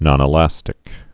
(nŏnĭ-lăstĭk)